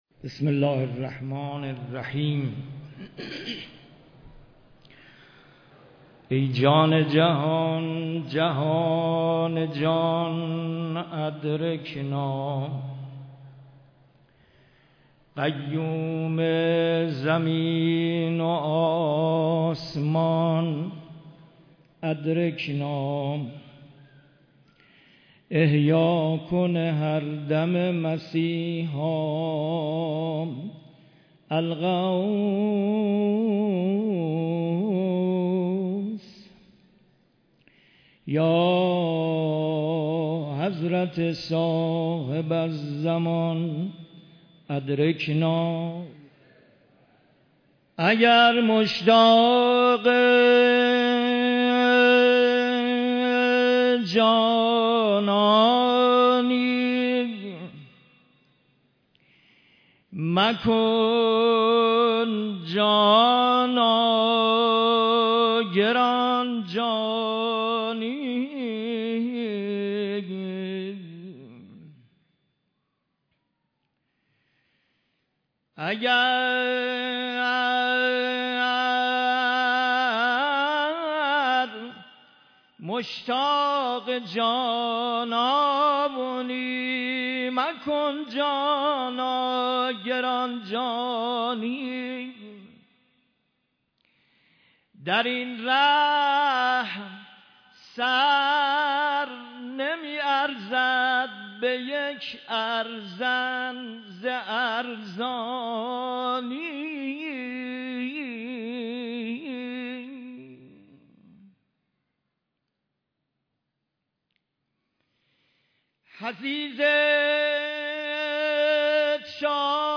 مداحی
رمضان 93
عقیق : در سالروز ضربت خوردن مولای متقیان حضرت امیرالمؤمنین علی علیه السلام، مراسم سوگواری و عزاداری آن امام همام، با حضور قشرهای مختلف مردم و رهبر معظم انقلاب اسلامی، در حسینیه امام خمینی(ره) برگزار شد.